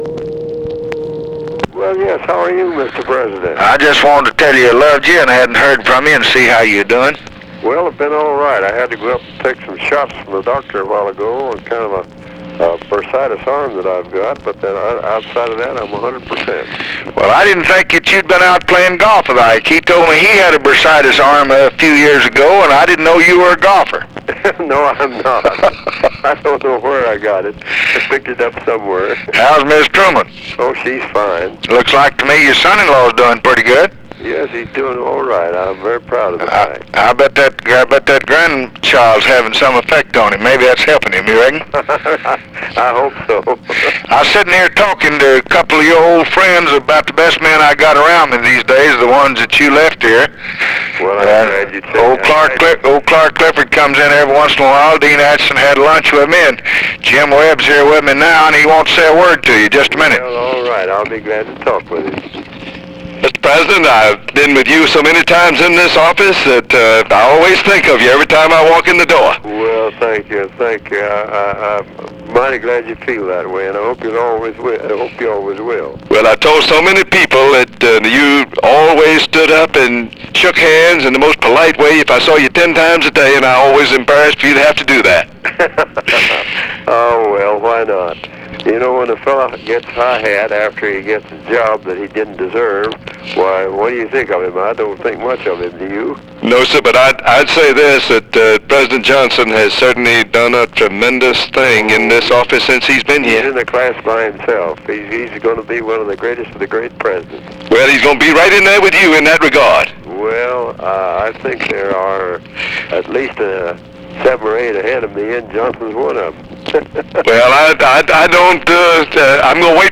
Conversation with HARRY TRUMAN and JAMES WEBB, September 8, 1964
Secret White House Tapes